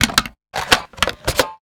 metahunt/flamethrower_reload_02.wav at dfc221d77e348ec7e63a960bbac48111fd5b6b76
flamethrower_reload_02.wav